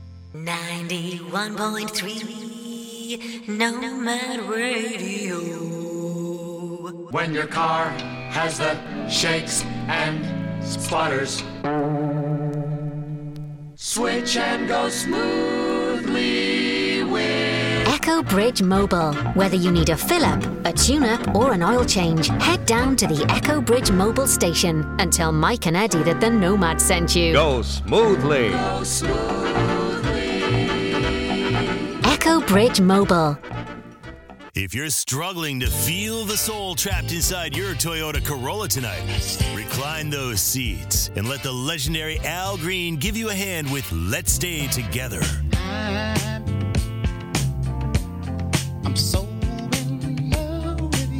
a radio commercial for them for Nomad Radio, our imaginary radio station.